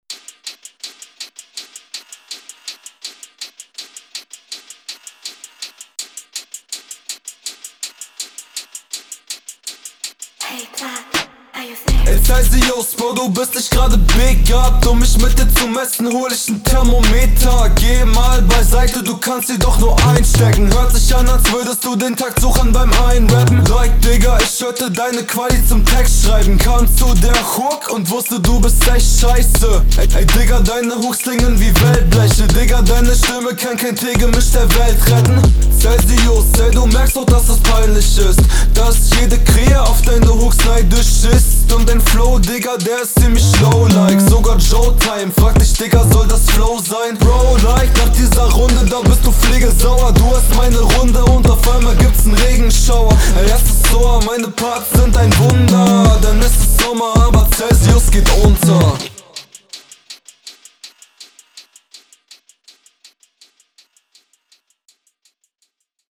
Jo rap und Stimme sind schon ziemlich dope.
sehr starker Stimmeinsatz auf dem Beat. Mix auch super.